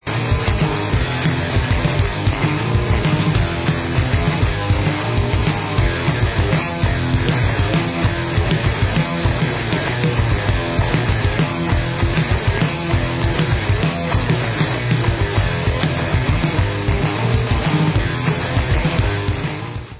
sledovat novinky v oddělení Rock/Alternative Metal